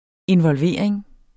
Udtale [ envʌlˈveɐ̯ˀeŋ ]